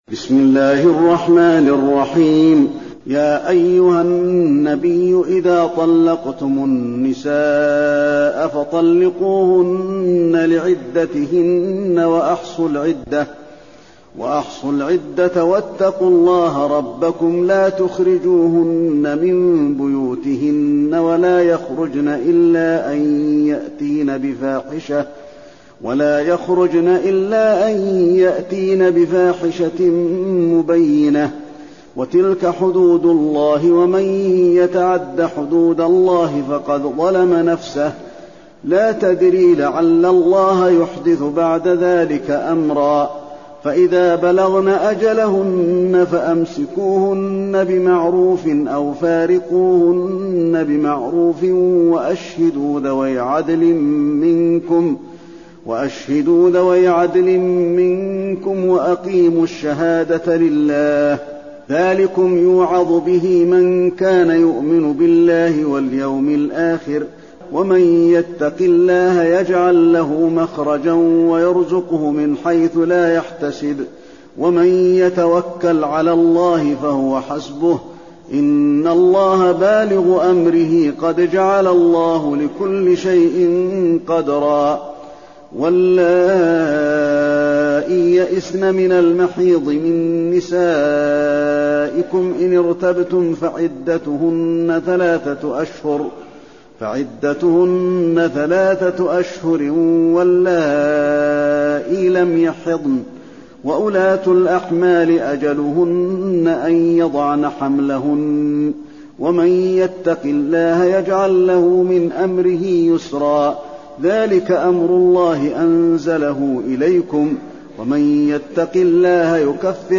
المكان: المسجد النبوي الطلاق